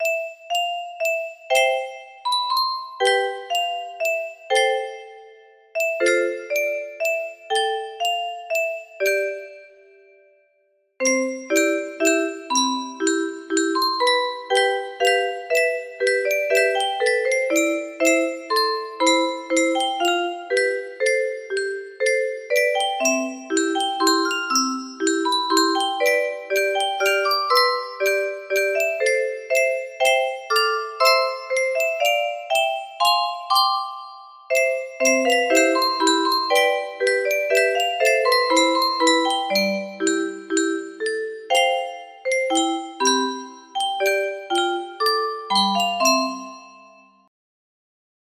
For Jason music box melody
Grand Illusions 30 (F scale)